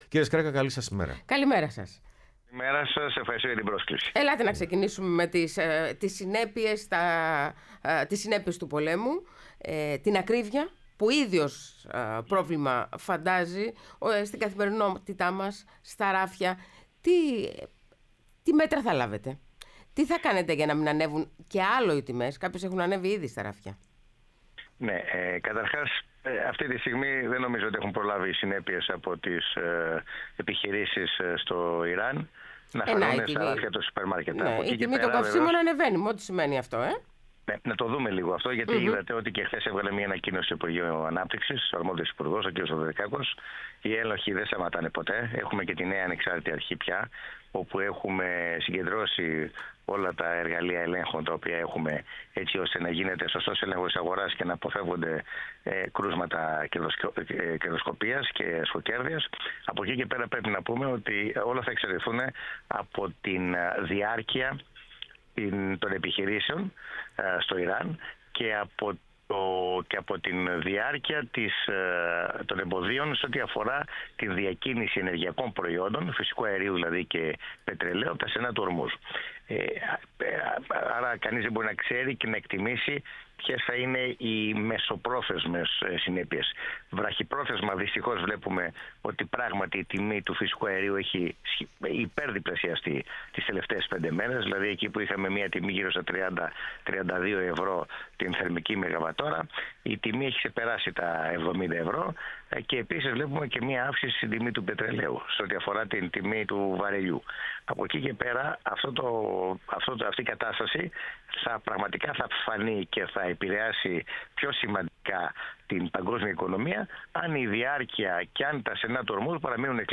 Ο Κώστας Σκρέκας, γραμματέας Πολιτικής Επιτροπής ΝΔ, μίλησε στην εκπομπή Πρωινές Διαδρομές
ΕΡΤNEWS RADIO